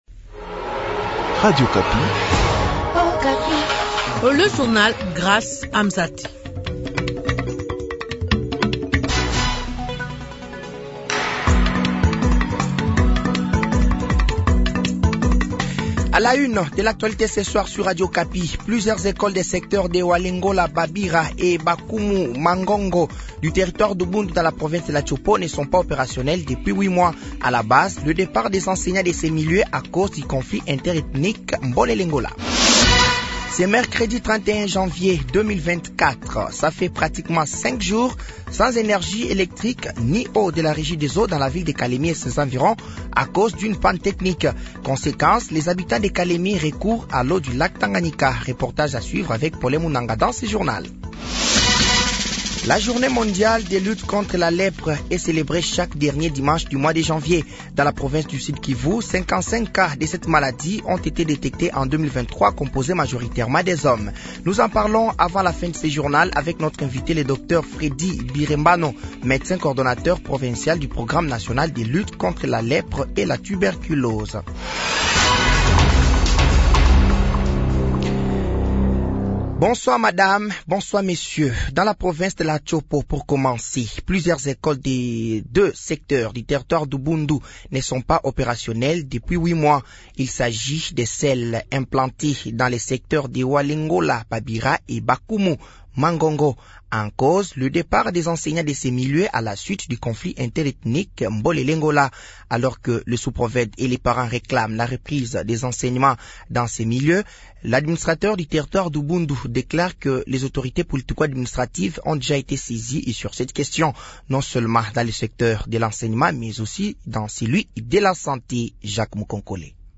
Journal français de 18h de ce mercredi 31 janvier 2024